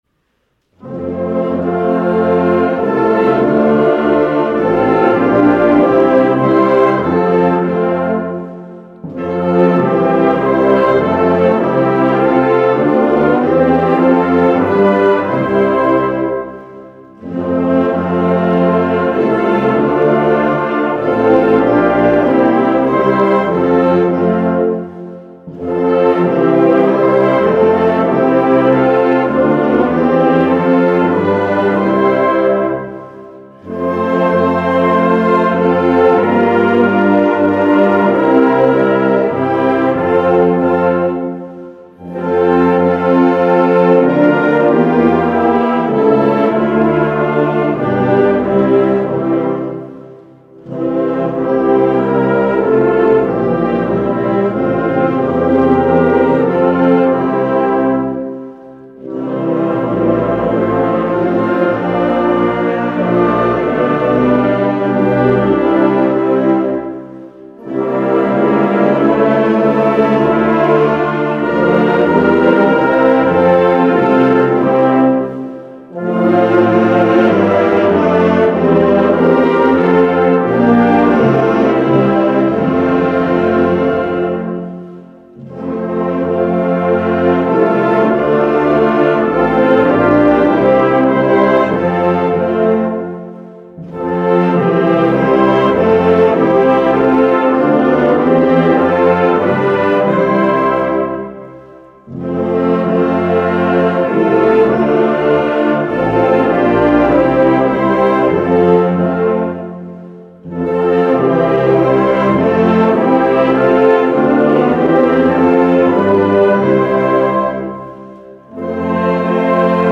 Gut klingende Tonart für Blasorchester und Laiengesang!!!
Bei diesem Arrangement können "Alle" mitsingen!